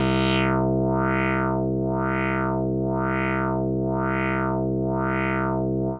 Index of /90_sSampleCDs/Trance_Explosion_Vol1/Instrument Multi-samples/LFO Synth
C3_lfo_synth.wav